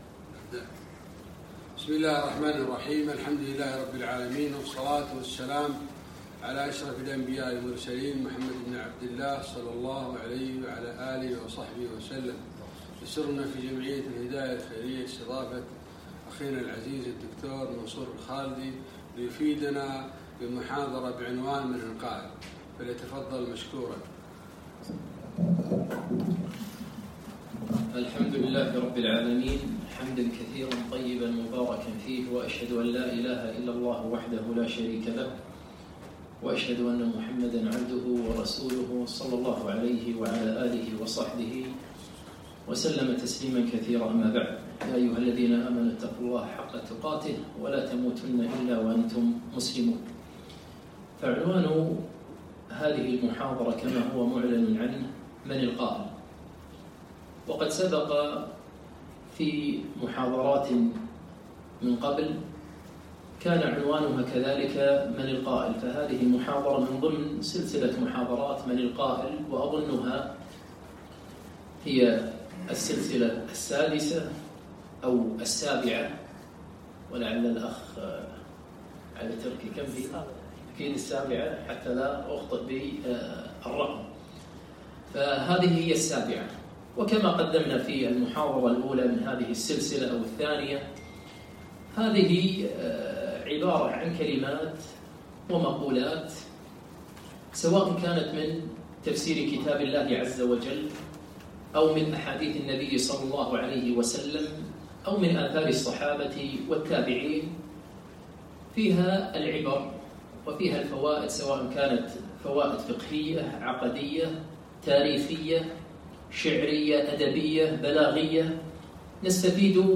7- من القائل ؟ - المحاضرة السابعة